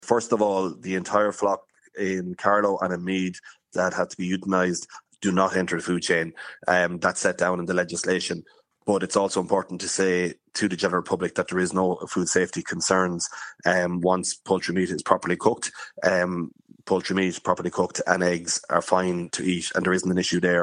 Minister for Agriculture and Kildare South TD Martin Heydon says the turkey and chicken should still be safe to eat.